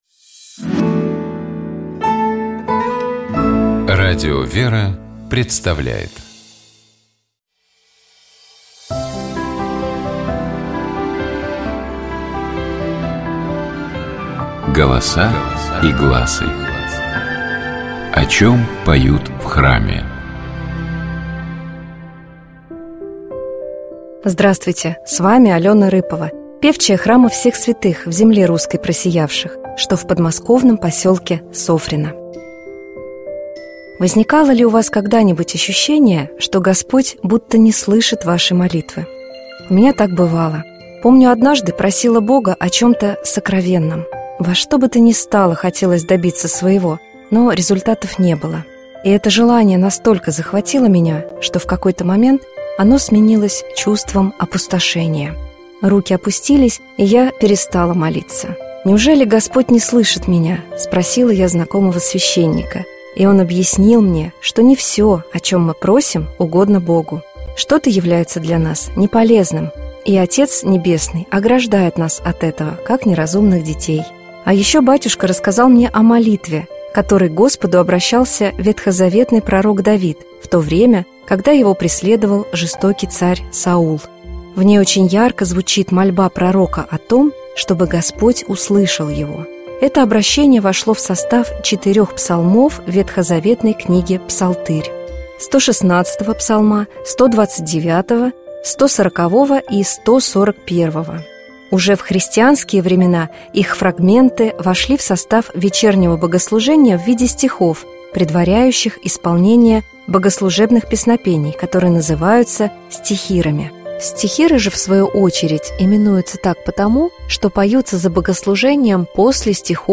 Давайте поразмышляем над текстом стихов из Псалтыри, предваряющих стихиры на «Господи, воззвах» и послушаем их в исполнении сестёр храма Табынской иконы Божией Матери Орской епархии.